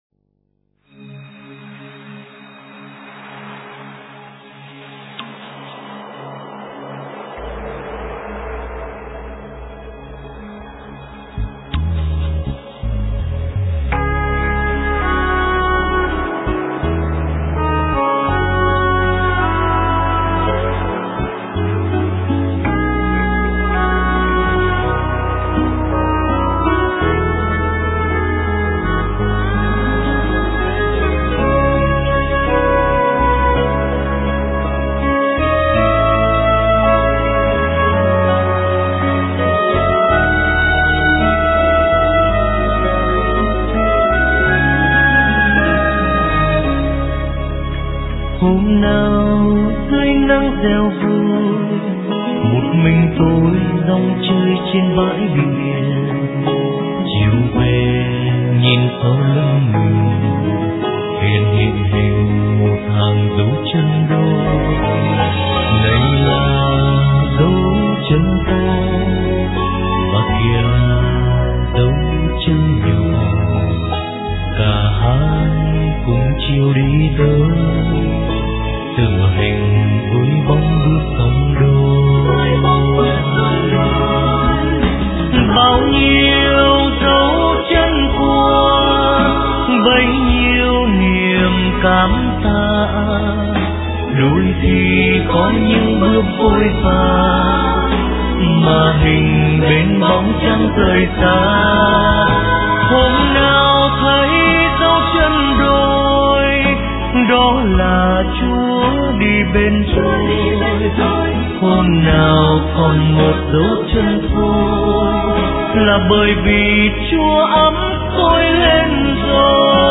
* Thể loại: Cuộc sống